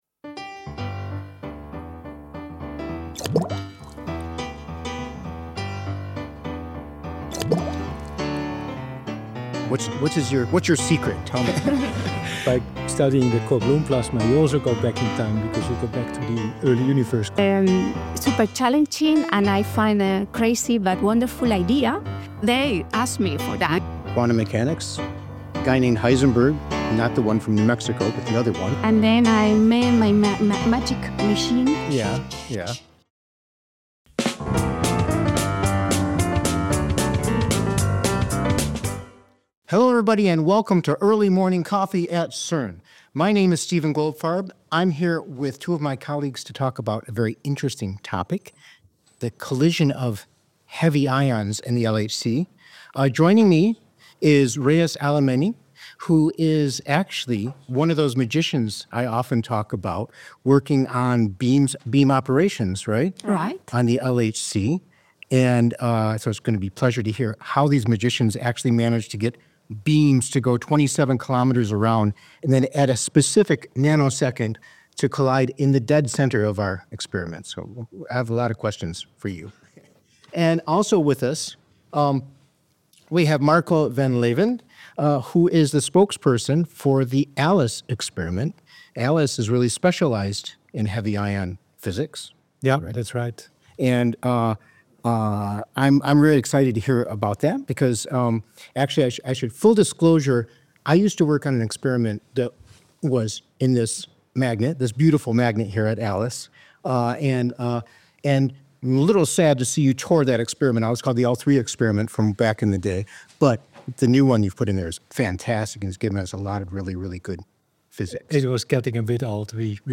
In this episode, we connect with an expert from the LHC’s Beam Operations team and a researcher from the ALICE experiment to uncover the science behind colliding lead ions instead of protons.